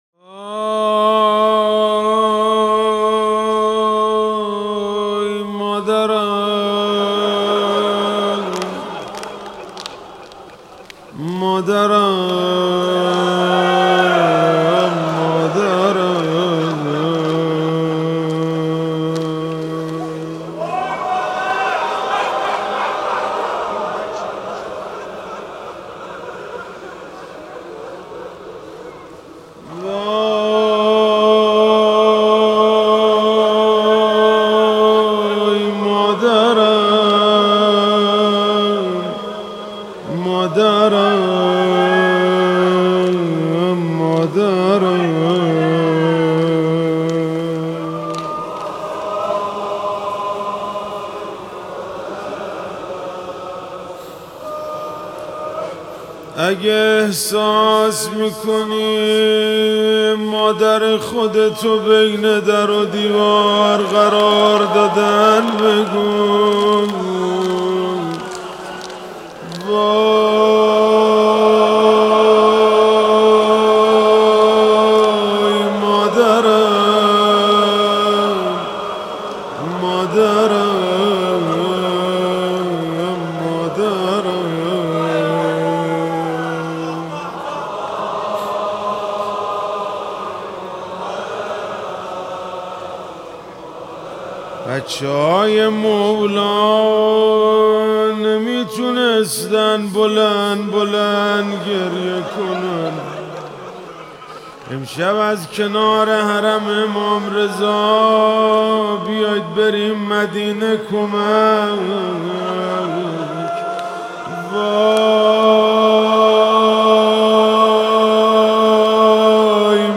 روضه حضرت زهرا سلام الله علیها